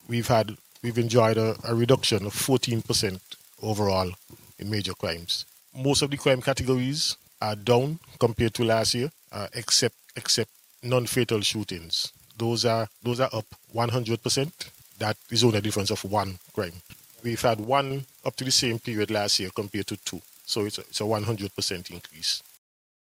Up to April 18th, 2026, or week 16, authorities reported a 14% reduction in major crimes compared with the same period last year. Deputy Commissioner of Police, Cromwell Henry, shared these figures: